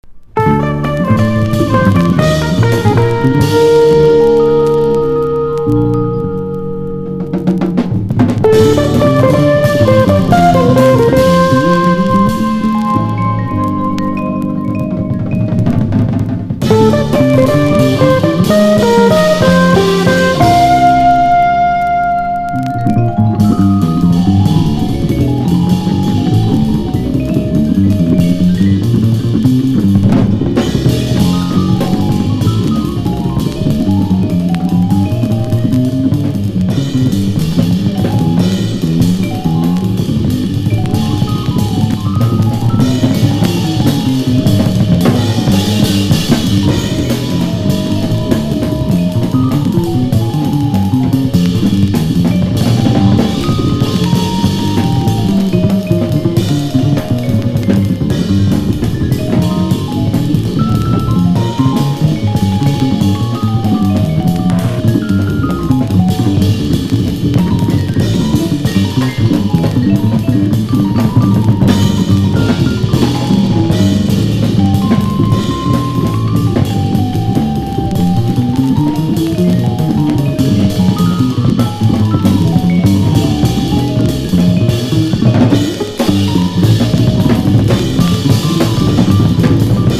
FUSION / JAZZ ROCK# FREE / SPIRITUAL
エレクトリック・フリー・ジャズ・ロック・クインテット!!